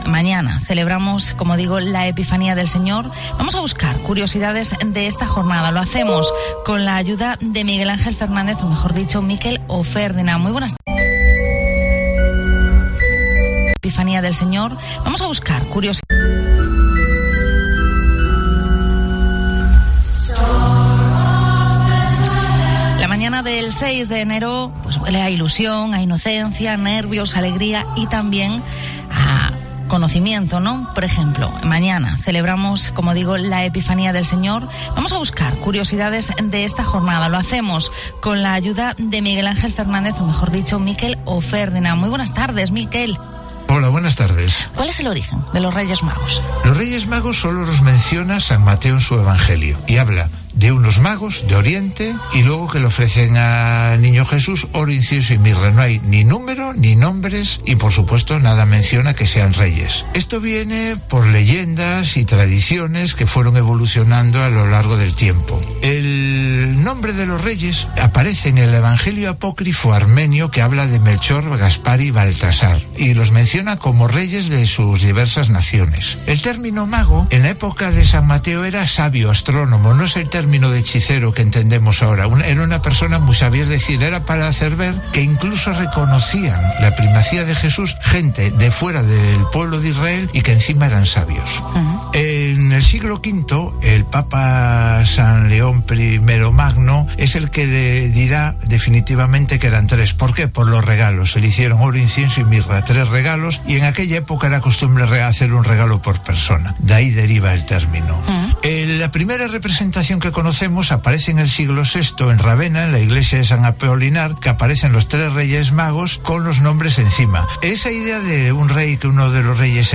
Vigo Entrevista ¿Cuál es el origen de los Reyes Magos?¿Siempre fue así su aspecto?